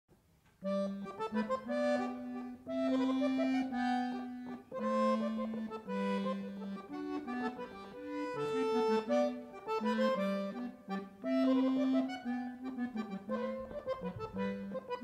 Je hoort 5 korte fragmenten van verschillende instrumenten.